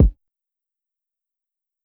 Kick (From Time).wav